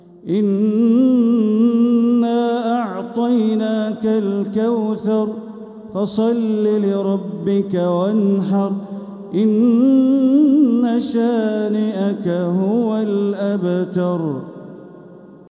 سورة الكوثر | مصحف تراويح الحرم المكي عام 1446هـ > مصحف تراويح الحرم المكي عام 1446هـ > المصحف - تلاوات الحرمين